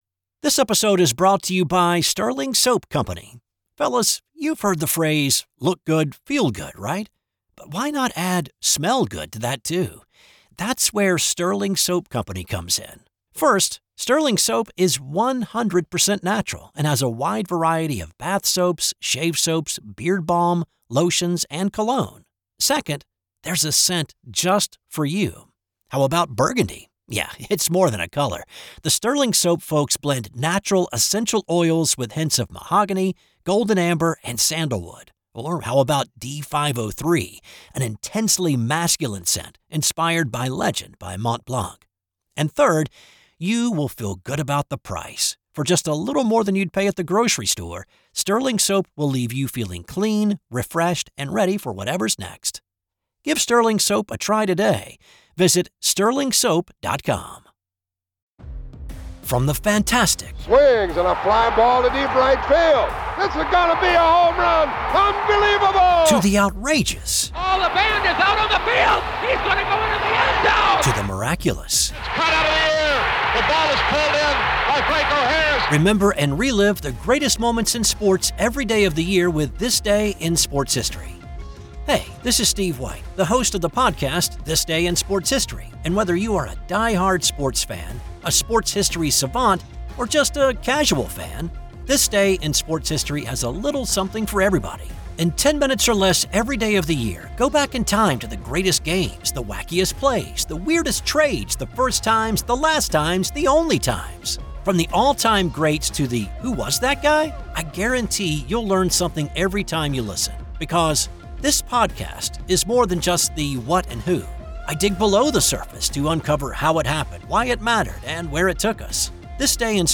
This-DiSH-Trailer-2024.mp3